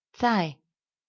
口音有声数据
口音（男声）